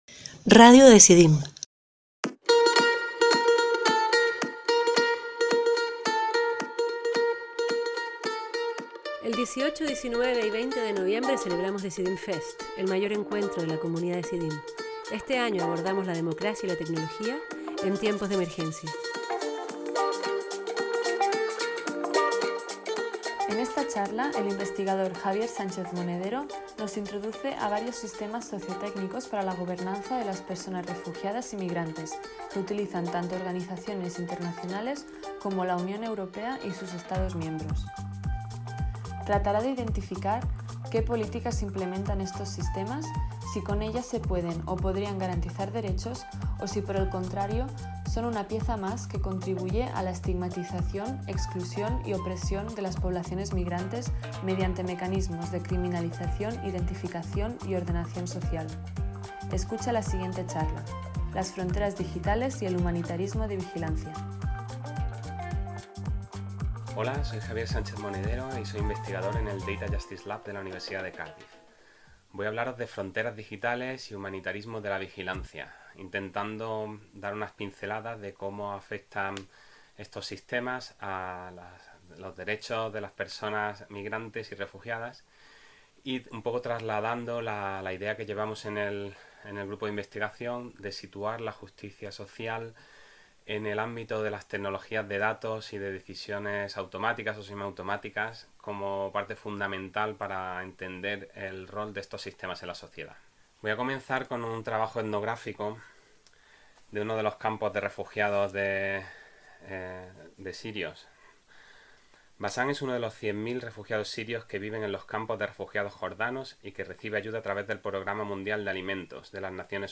En esta charla introduciremos varios sistemas sociotécnicos para la gobernanza de las personas refugiadas y migrantes que utilizan tanto organizaciones internacionales como la Unión Europea y sus estados miembros. Trataremos de identificar qué políticas implementan estos sistemas, si con ellas se pueden o podrían garantizar derechos, o si por el contrario son una pieza más que contribuye a la estigmatización, exclusión y opresión de las poblaciones migrantes mediante mecanismos de criminalización, identificación y ordenación social.
Programa: Decidim Fest 20